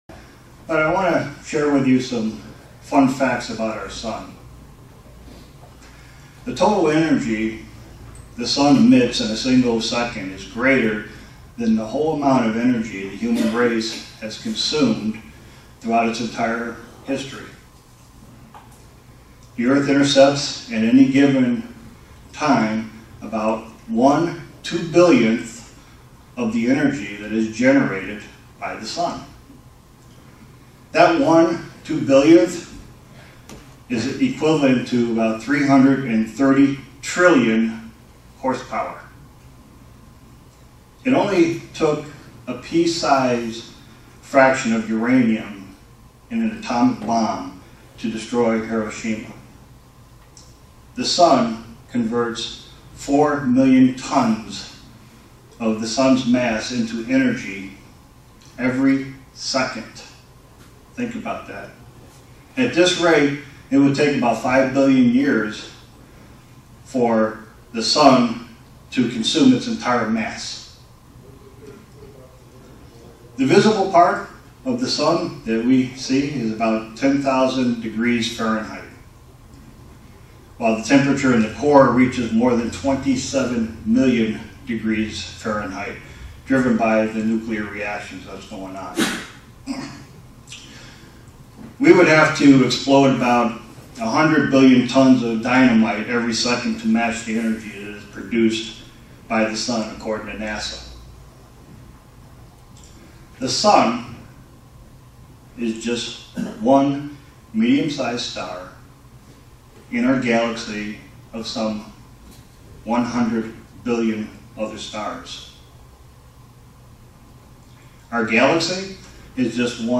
Print How the whole creation shows the power of the Holy Spirit. sermon Studying the bible?